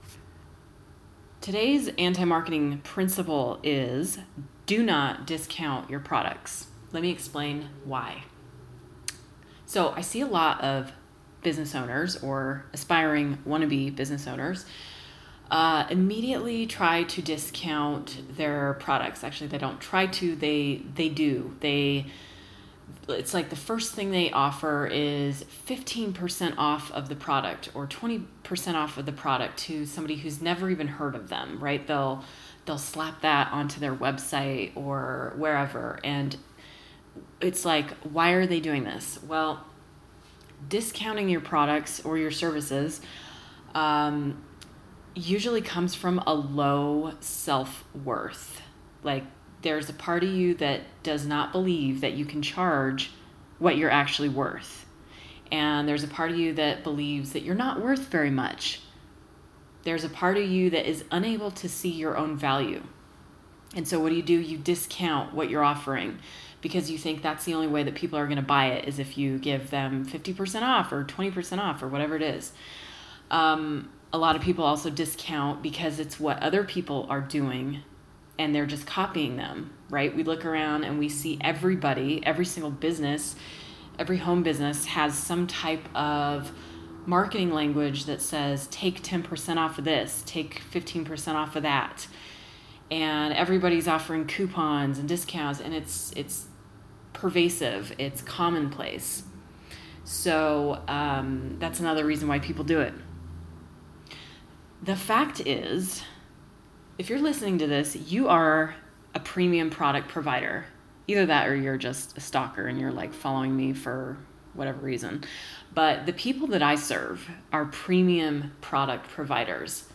[Rant] Don’t discount!
a short 5-minute rant about discounts and why it’s a bad idea to offer discounts on premium products.
(Sorry if I sound pissed!…I am!